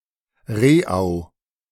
Rehau (German pronunciation: [ˈʁeːaʊ̯]
De-Rehau.ogg.mp3